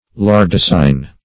Search Result for " lardacein" : The Collaborative International Dictionary of English v.0.48: Lardacein \Lar`da*ce"in\, n. [See Lardaceous .]